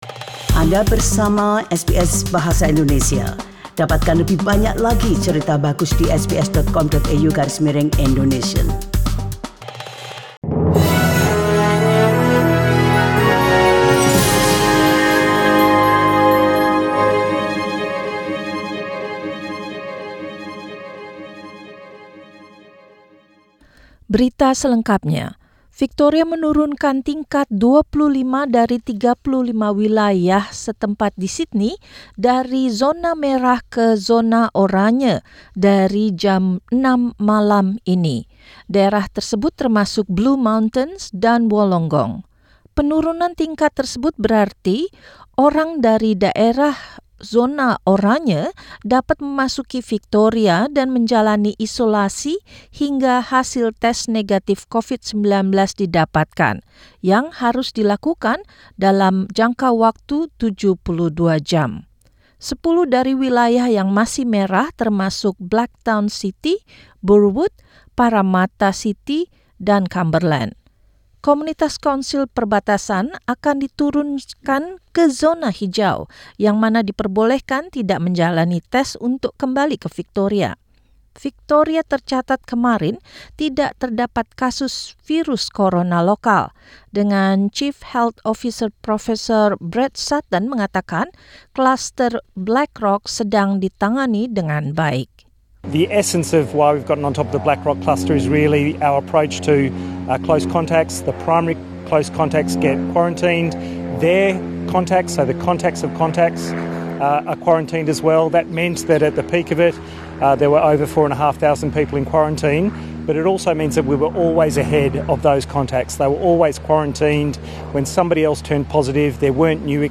SBS Radio News delivered in Indonesian - 18 January 2021